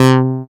95 CLAV   -R.wav